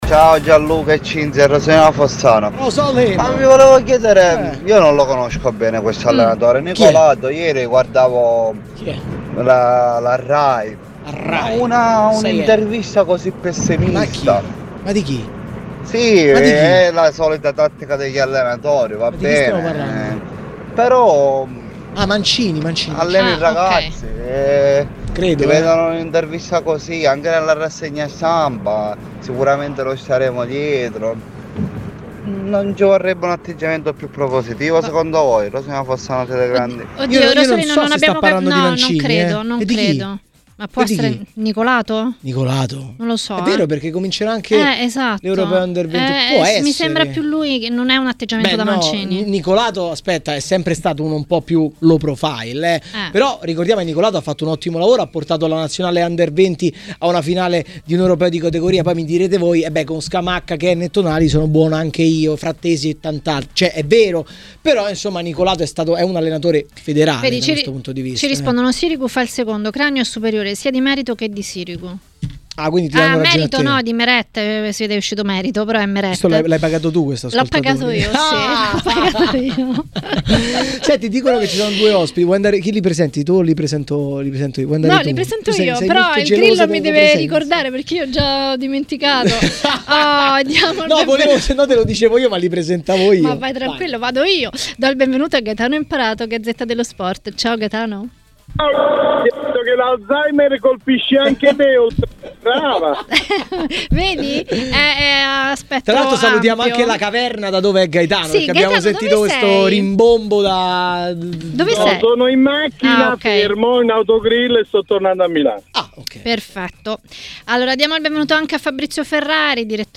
A parlare dei temi della Serie A a Maracanà, nel pomeriggio di TMW Radio, è stato l'ex calciatore e tecnico Bruno Giordano.